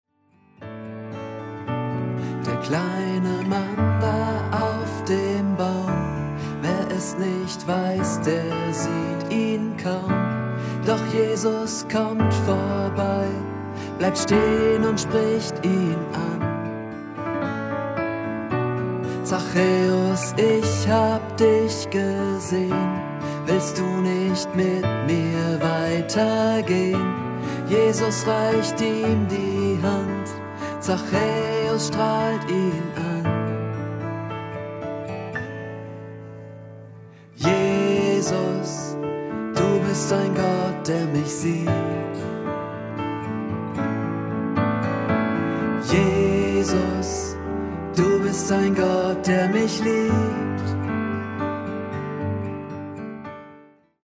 . Eingängige Melodien und einfache Texte.